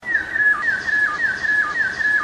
Waldgeräusch 1: Waldvogel / forest sound 1: forest bird